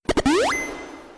single_heal.wav